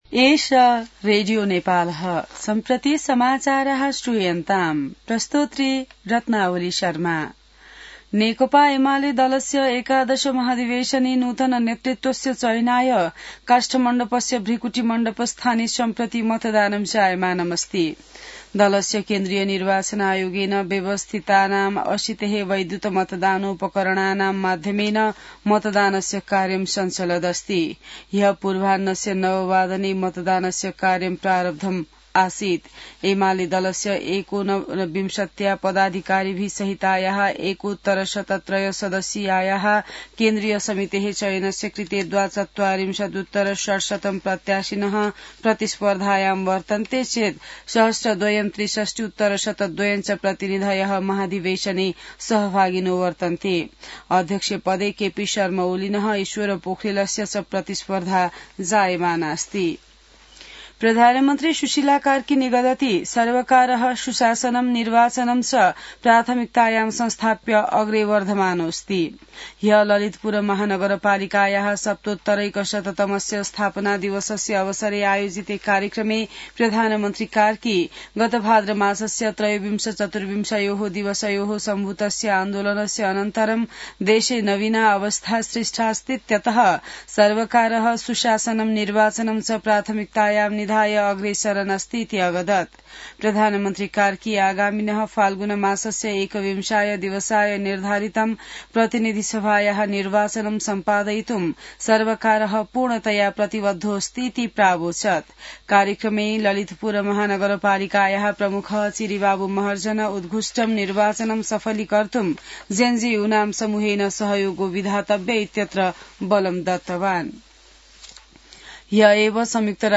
संस्कृत समाचार : ३ पुष , २०८२